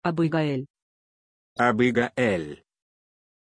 Pronunția numelui Abygael
pronunciation-abygael-ru.mp3